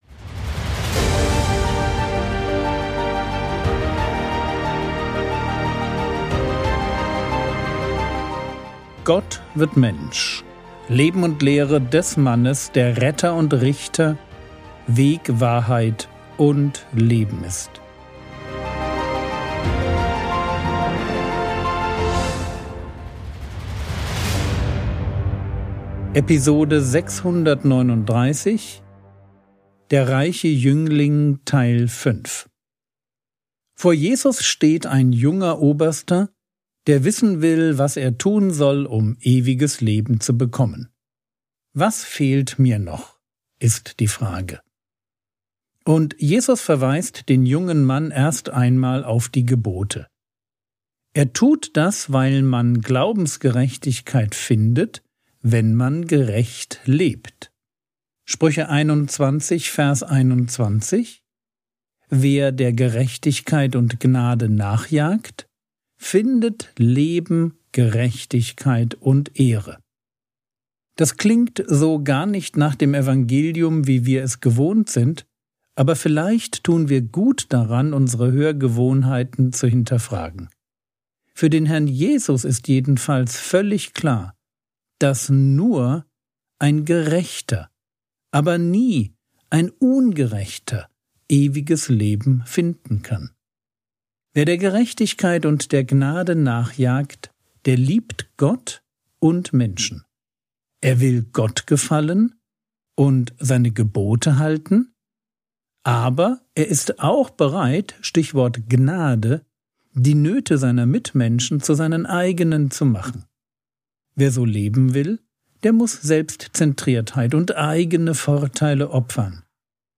Episode 639 | Jesu Leben und Lehre ~ Frogwords Mini-Predigt Podcast